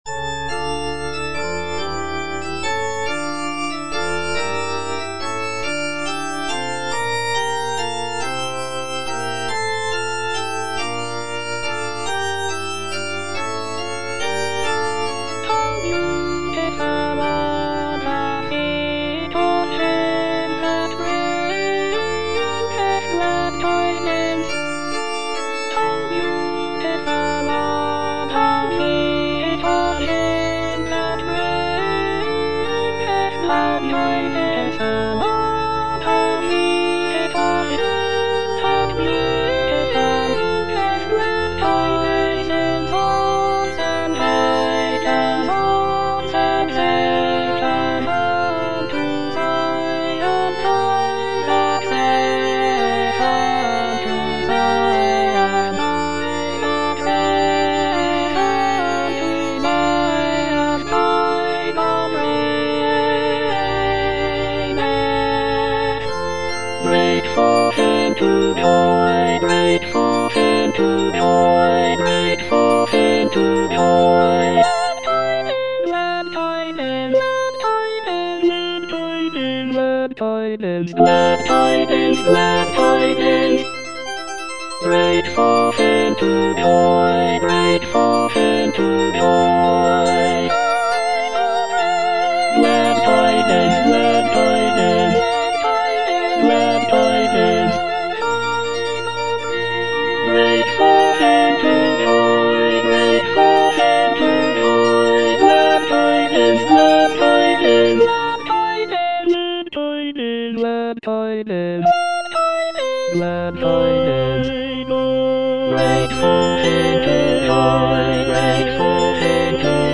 (All voices) Ads stop